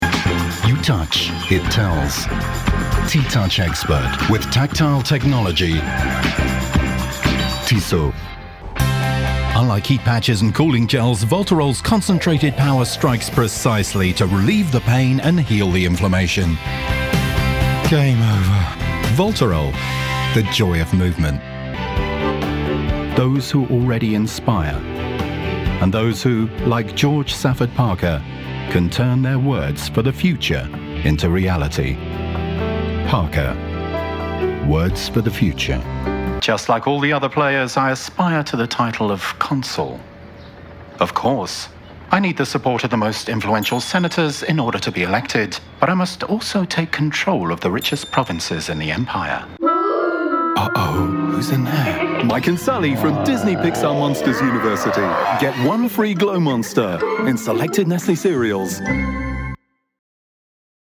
a warm, neutral and versatile British voice
a neutral UK voice